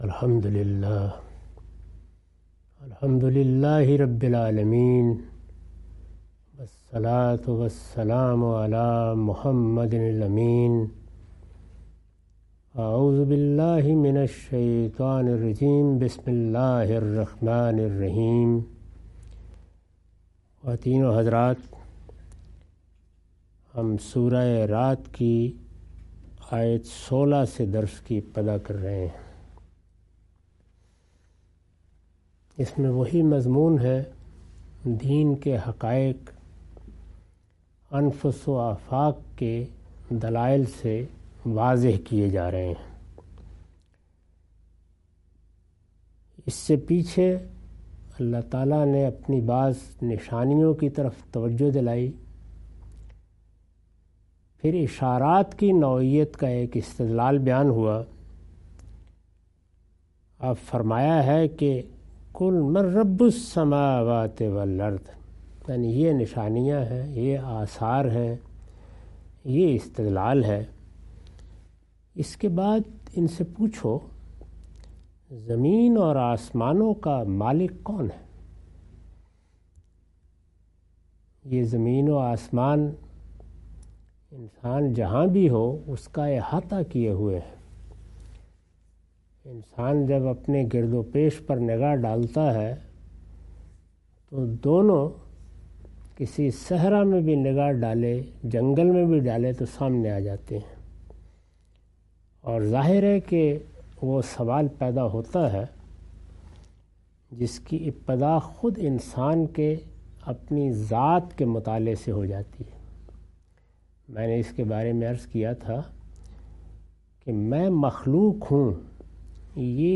Surah Ar-Rad - A lecture of Tafseer-ul-Quran – Al-Bayan by Javed Ahmad Ghamidi. Commentary and explanation of verses 16-17.